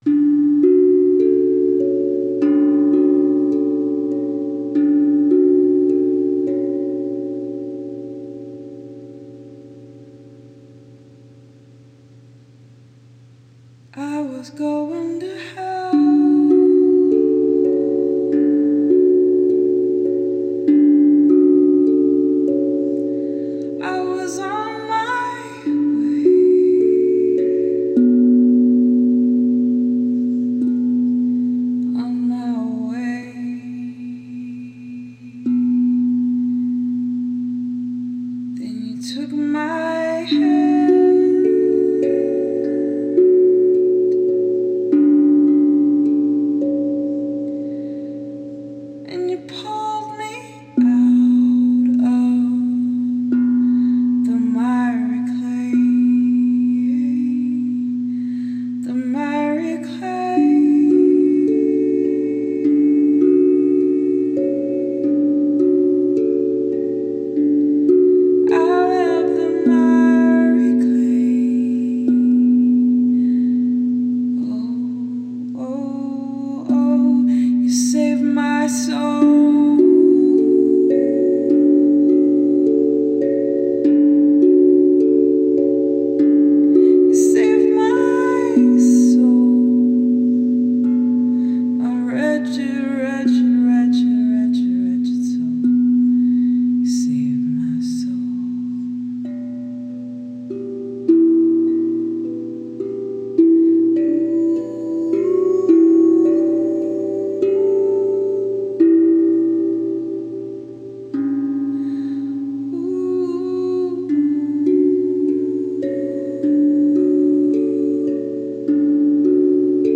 Amahi drum
improvised worship
spontaneous worship # tongue drum